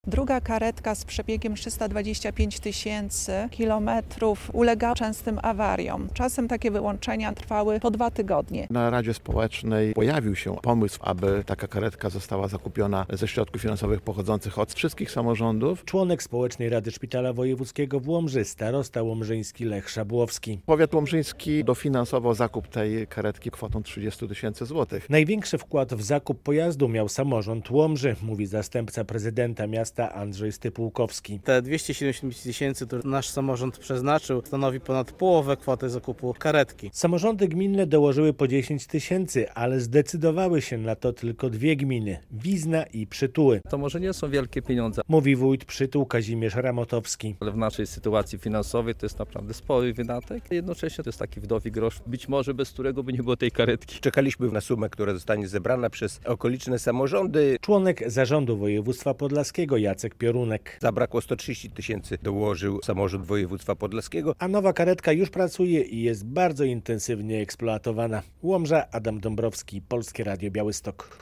Nowa karetka w Szpitalu Wojewódzkim w Łomży - relacja